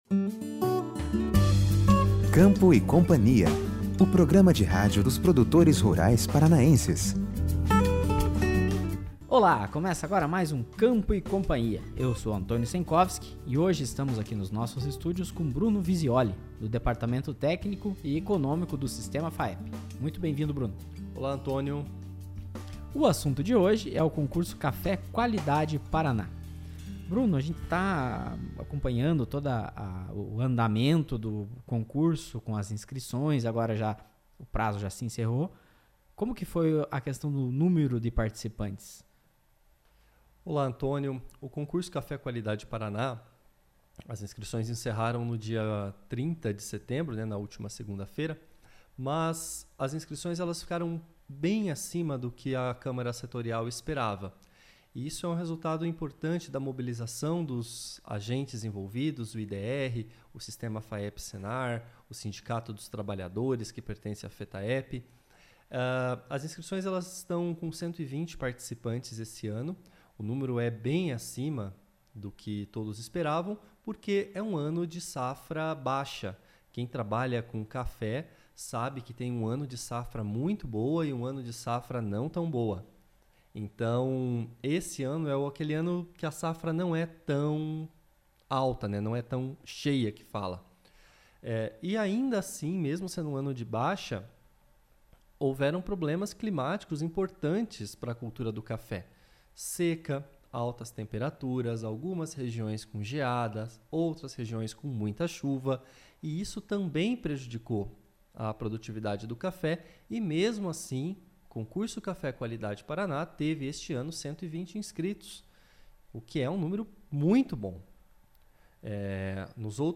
A entrevista na íntegra